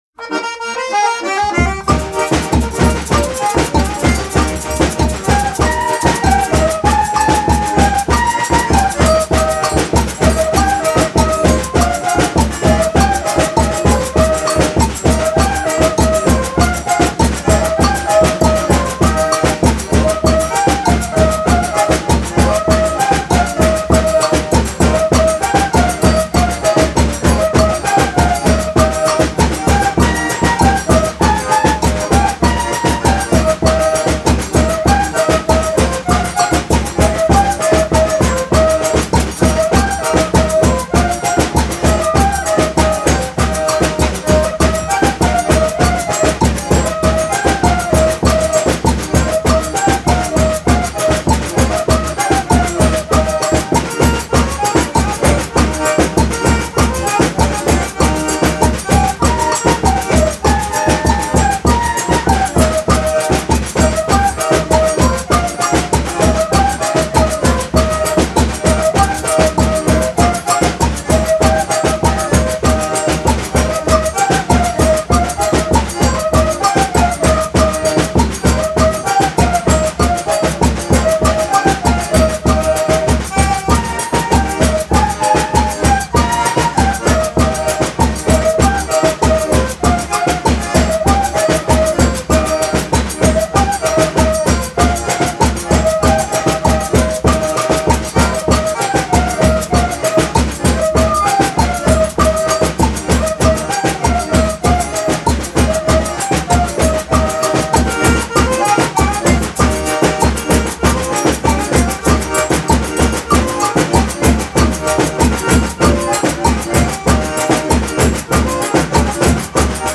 714   01:59:00   Faixa:     Folclore Brasileiro